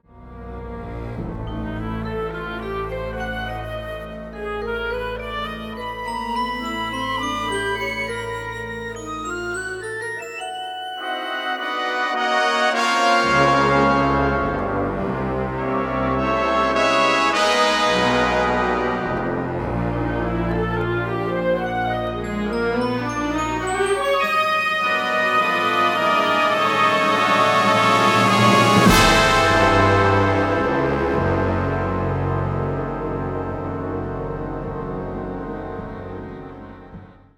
Nine New Works for Winds & Percussion
This new concert band studio recording